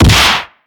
takedmg_1.ogg